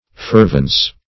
Fervence \Fer"vence\, n. Heat; fervency.